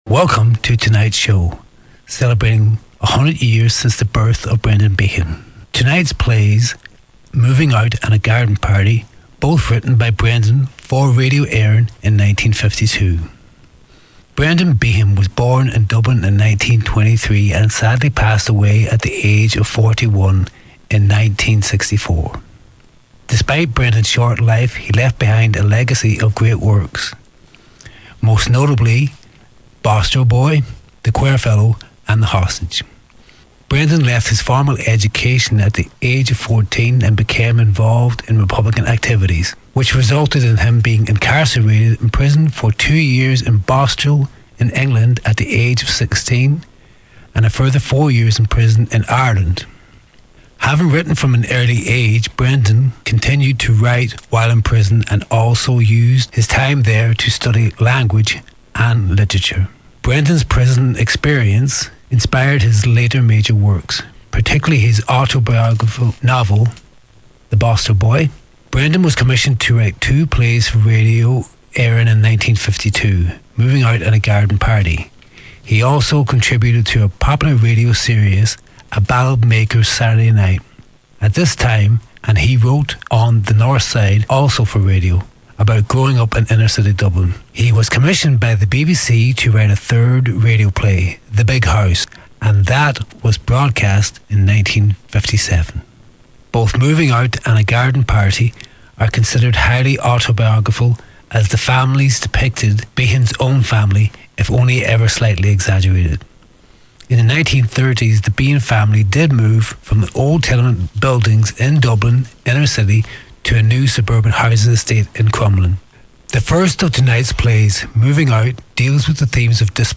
Drama: Moving Out
The Elusive Theatre Group present ‘Moving Out’ to celebrate Behan 100 as part of the Cathedral Quater Letterkenny Literary Festival.